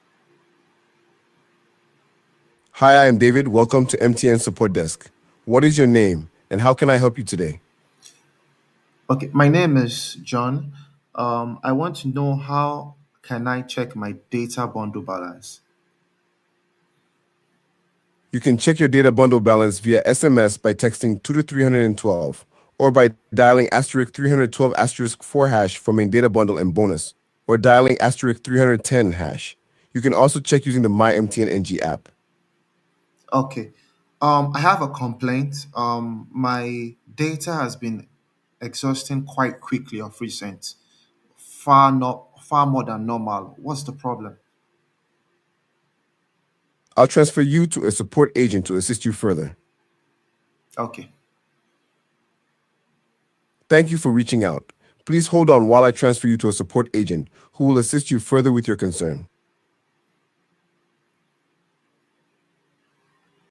subscription-based army of call center voice agents
with 80+ African voices.